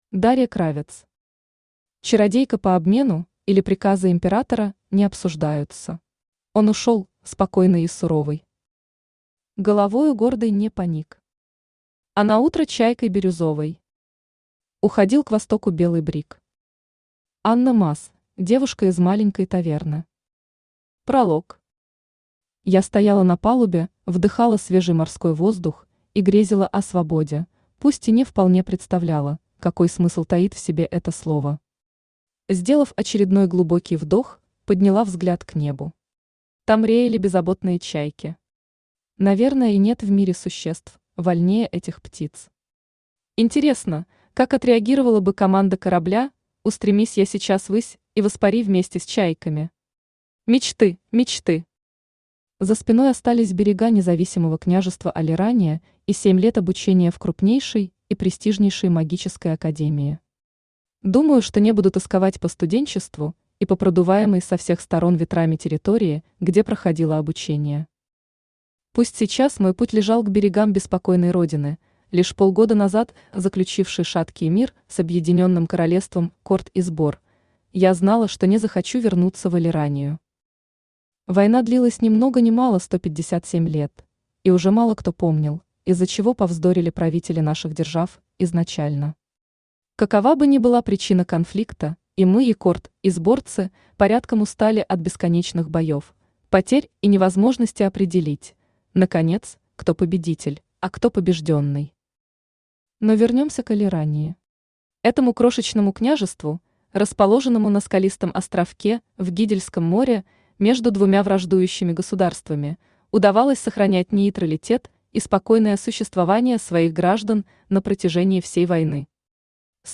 Аудиокнига Чародейка по обмену, или Приказы императора не обсуждаются | Библиотека аудиокниг
Aудиокнига Чародейка по обмену, или Приказы императора не обсуждаются Автор Дарья Кравец Читает аудиокнигу Авточтец ЛитРес.